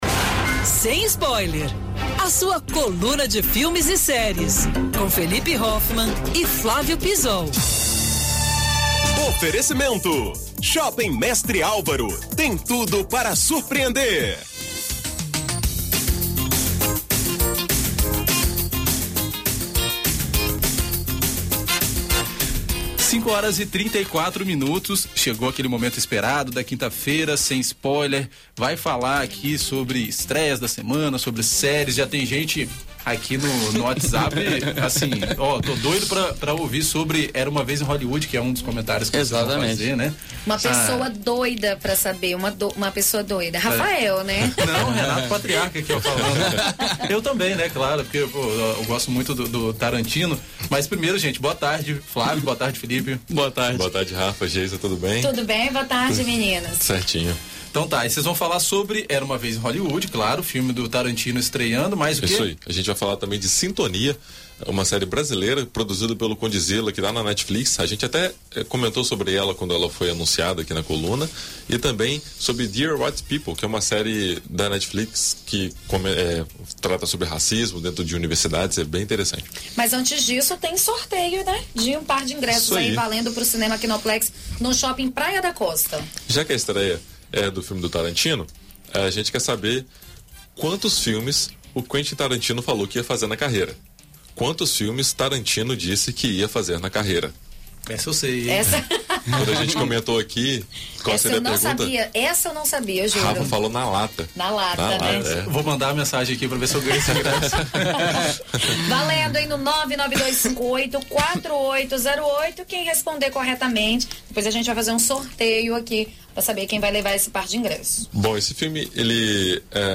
Na coluna Sem Spoiler desta semana, os comentaristas destacam a estreia de Era Uma Vez em Hollywood, obra do cineasta Quentin Tarantino.